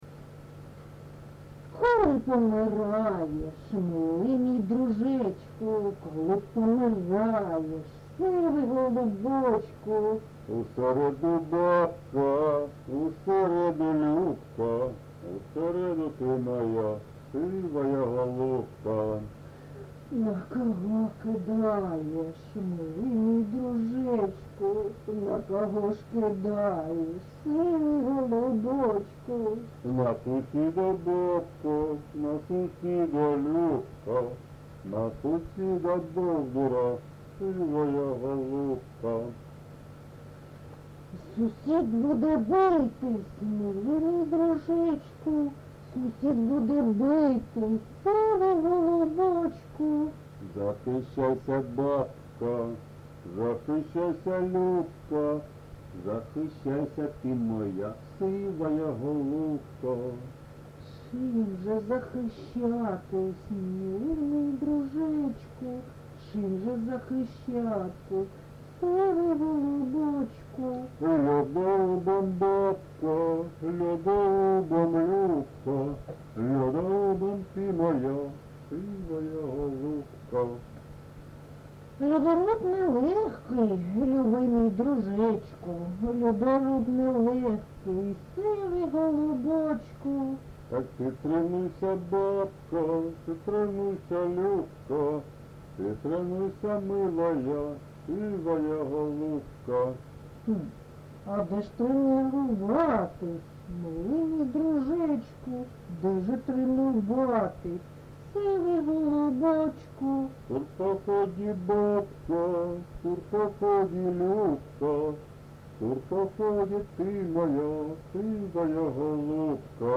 ЖанрЖартівливі
Місце записус. Чорнухине, Алчевський район, Луганська обл., Україна, Слобожанщина